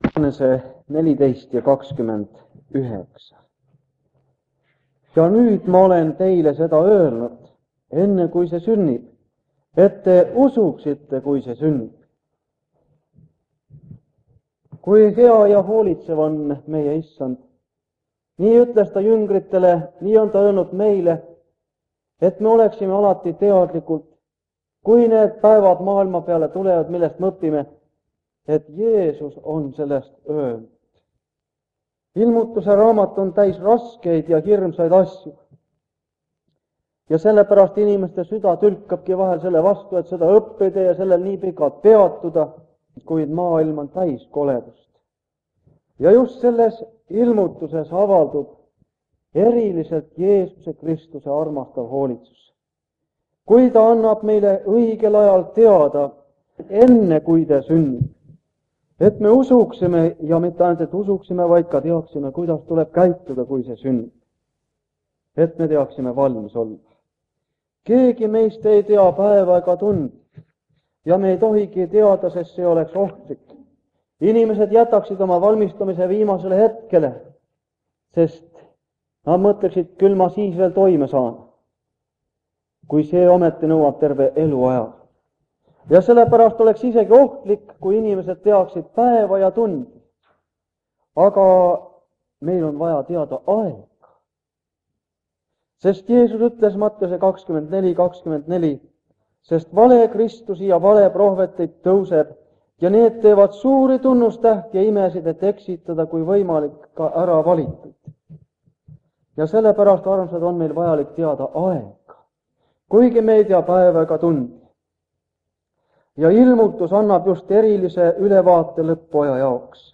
Jutlused
Ilmutuse raamatu seeriakoosolekud Kingissepa linna adventkoguduses